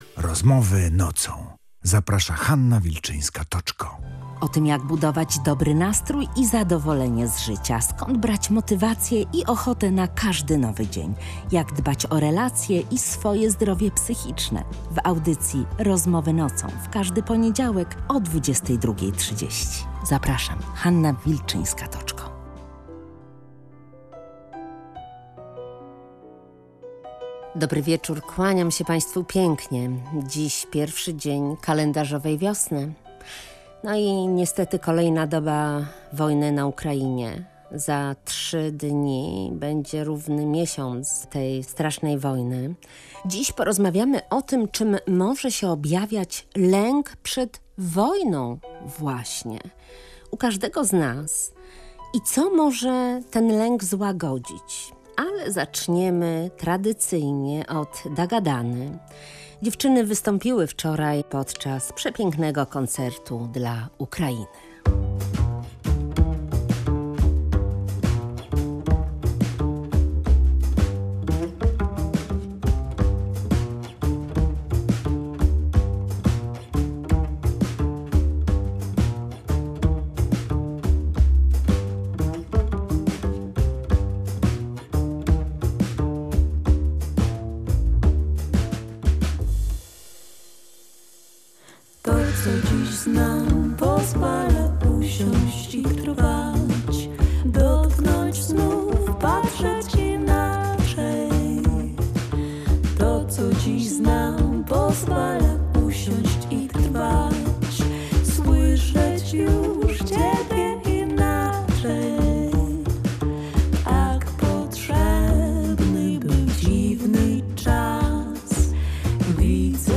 archiwum audycji